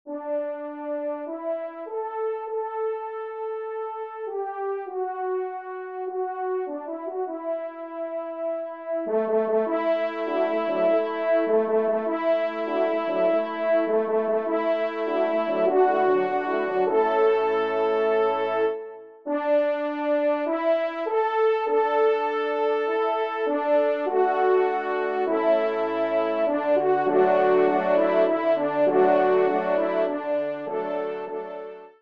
Genre :  Divertissement pour Trompes ou Cors & Orgue
ENSEMBLE